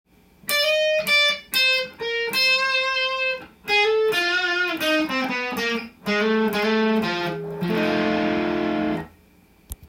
ピッキング通常ポジション
センターピックアップマイクの少し前のところです。
この場所を弾くことで一般的な普通の音が出ますので
試しに弾いてみましたが、やはり普通の音ですね。